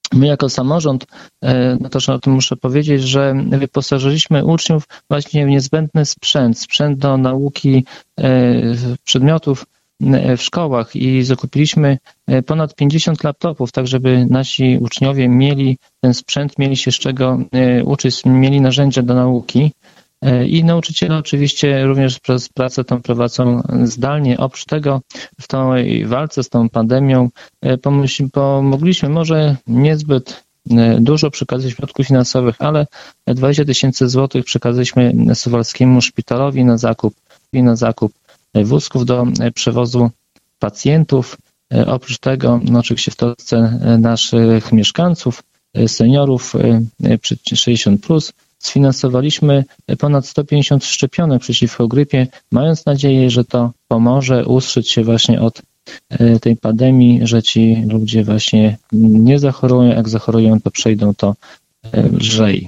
Mijający rok podsumował w środę (16.12) w Radiu 5 Zbigniew Mackiewicz, wójt gminy Suwałki.